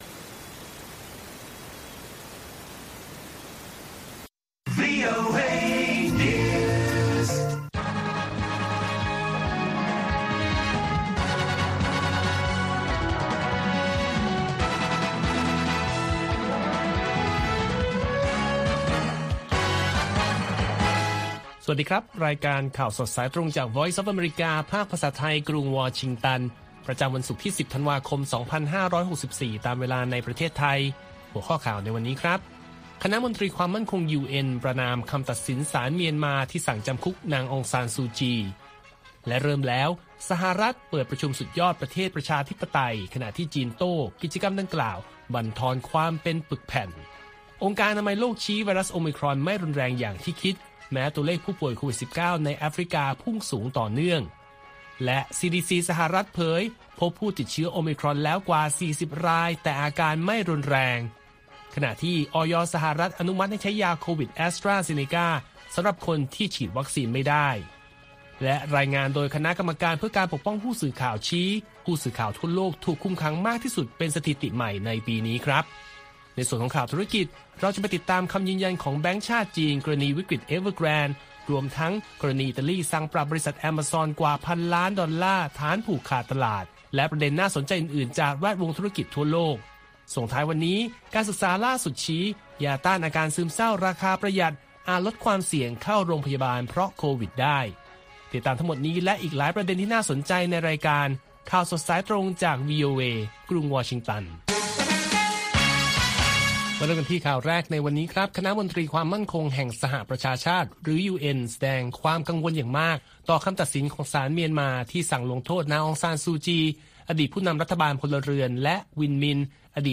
ข่าวสดสายตรงจากวีโอเอ ภาคภาษาไทย ประจำวันศุกร์ที่ 10 ธันวาคม 2564 ตามเวลาประเทศไทย